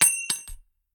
nut_impact_06.ogg